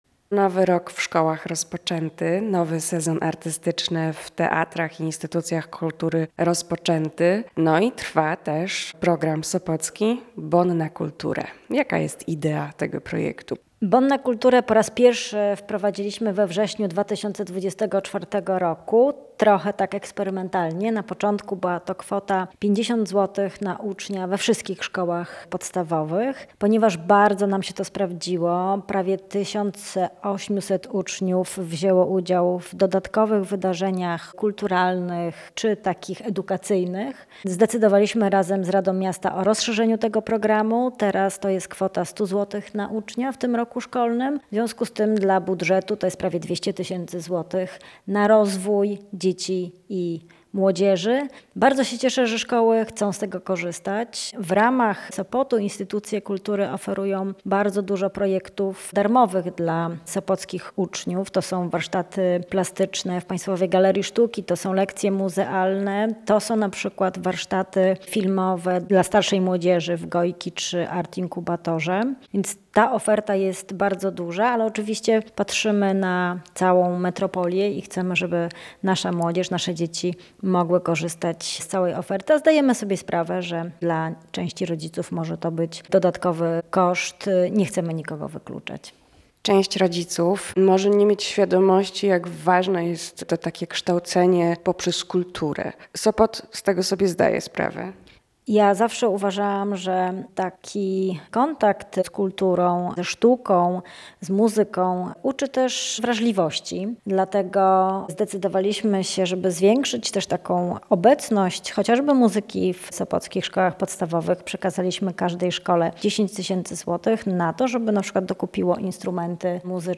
Bon na kulturę, bezpłatne warsztaty plastyczne i filmowe, dofinansowanie do zakupu instrumentów muzycznych w szkołach podstawowych – to niektóre z działań Sopotu umożliwiających dzieciom i młodzieży kształcenie poprzez spotkanie z kulturą. Gościem Radia Gdańsk jest Magdalena Czarzyńska-Jachim, prezydent Sopotu.